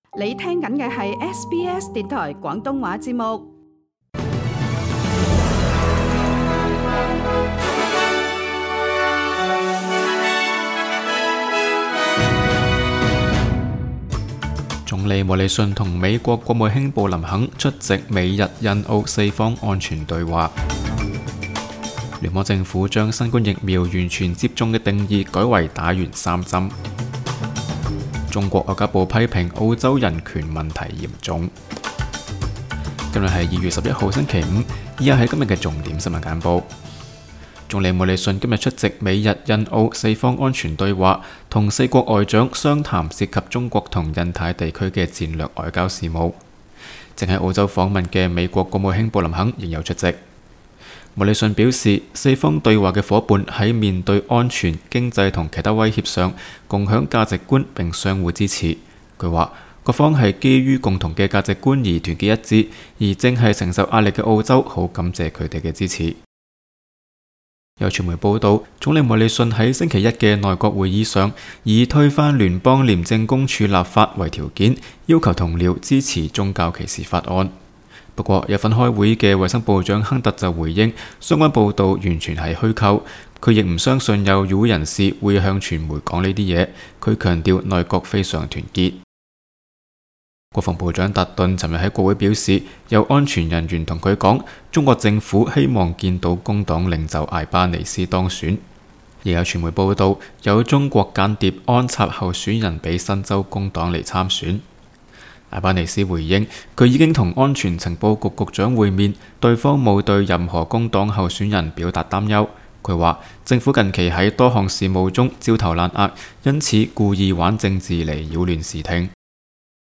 SBS 新聞簡報（2月11日）